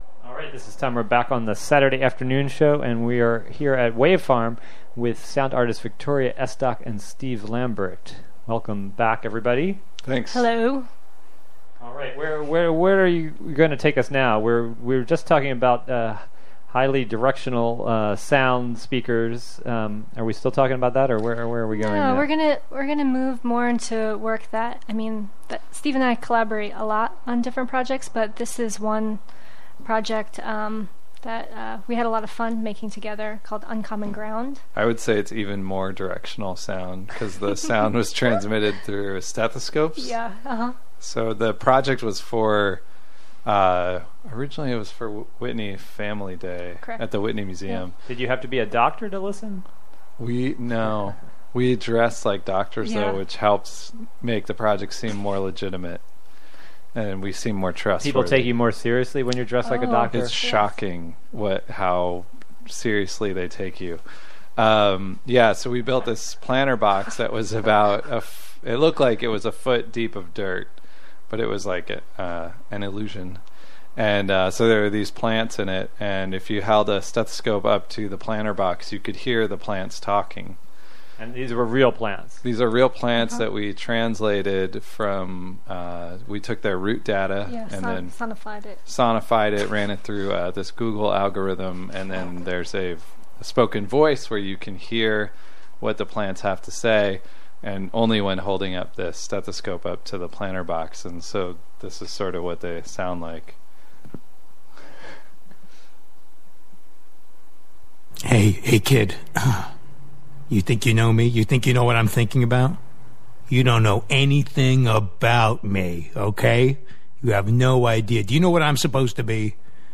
Transmission Arts & Experimental Sounds